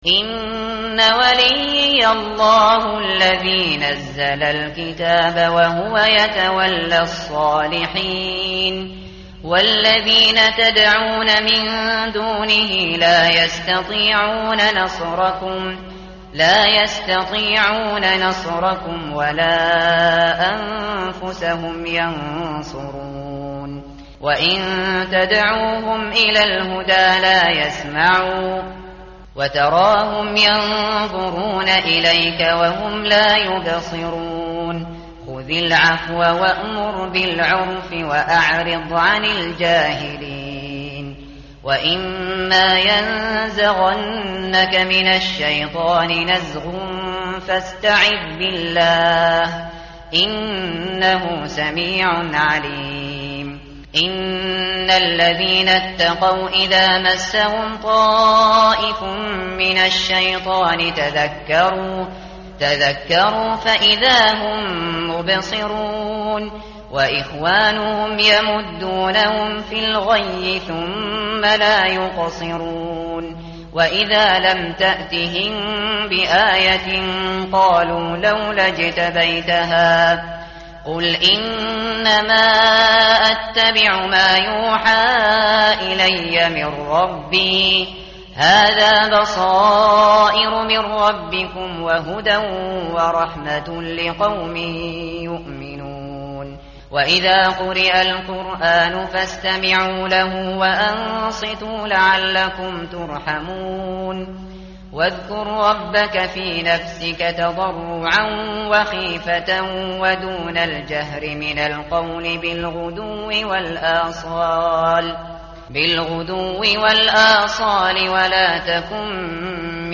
tartil_shateri_page_176.mp3